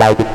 tekTTE63002acid-A.wav